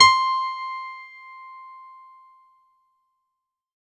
R  C5  DANCE.wav